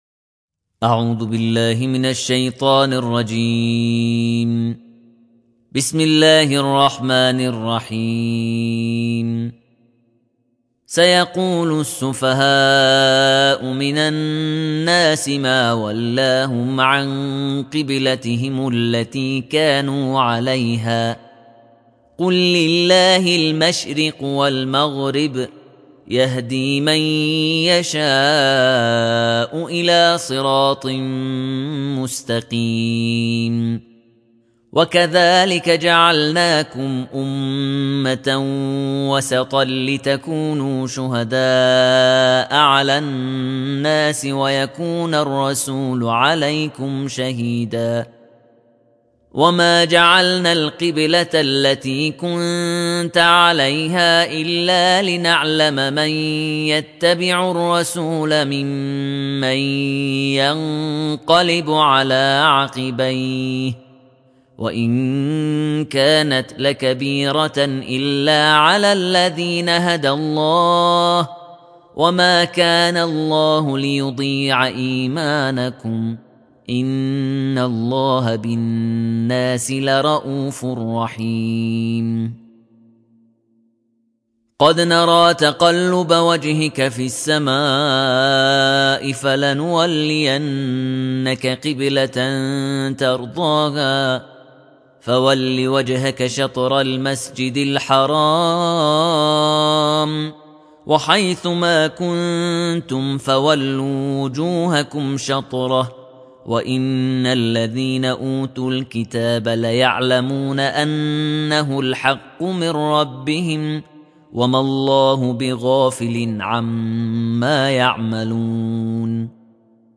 تلاوت ترتیل جزء دوم قرآن کریم در دومین روز از مهمانی خدا را می‌شنویم.